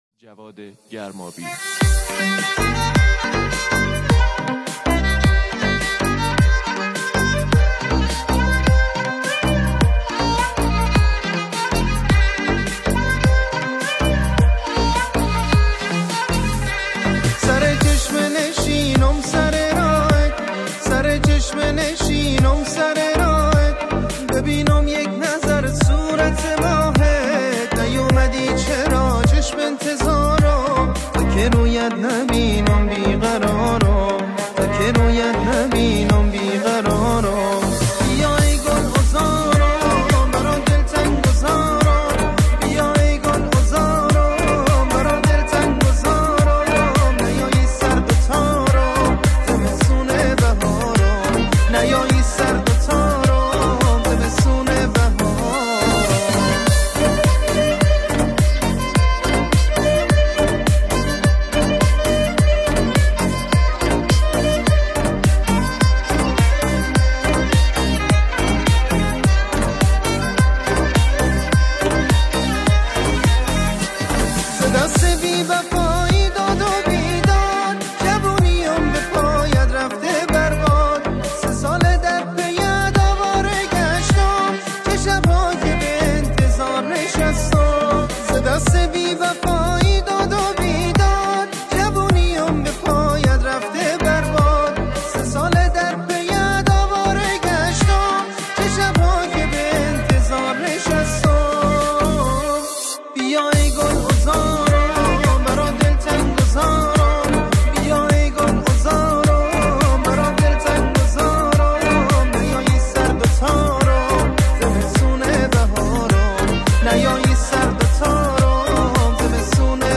کمانچه
آهنگ محلی
یک ترانه عاشقانه و احساسی شاد